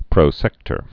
(prō-sĕktər)